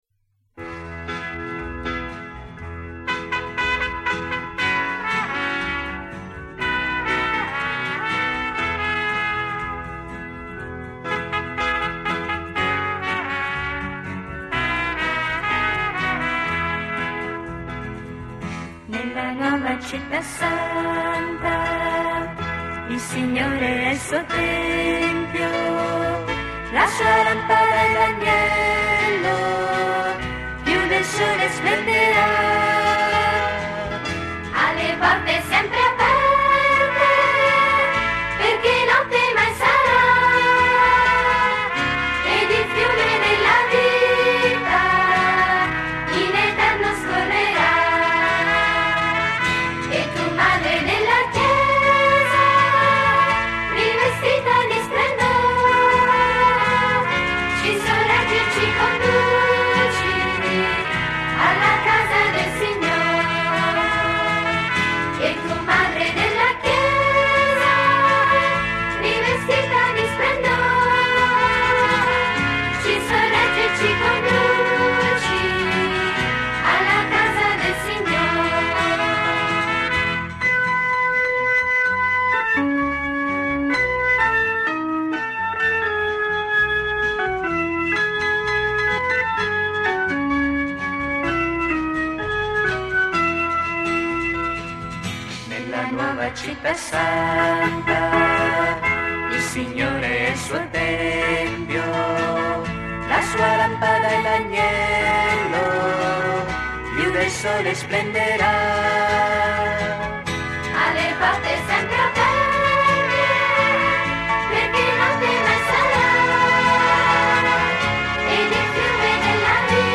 Canto per Rosario e Parola di Dio: Nella nuova Città santa